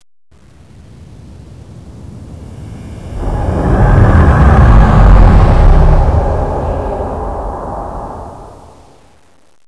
Ship Decloaks
decloak.wav